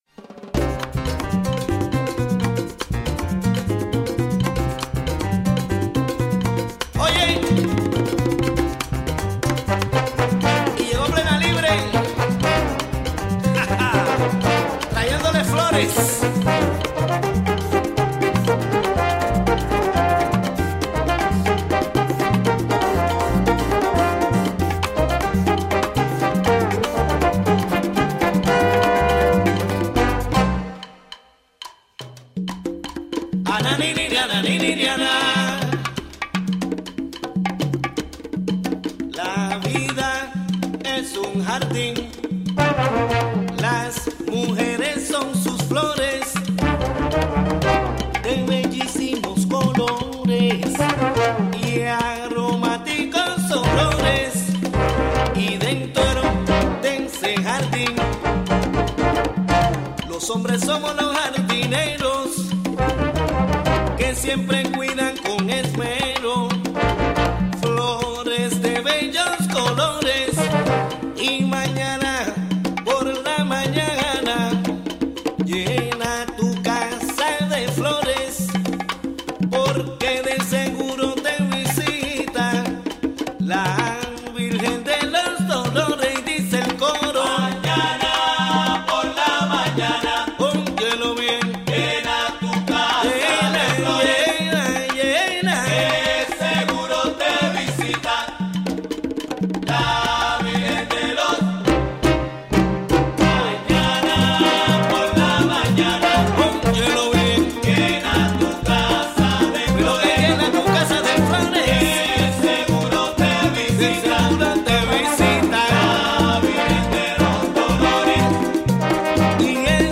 Discussion about the upcoming local events to commemorate the Rev. Dr. Martin Luther King Jr's birthday. Interview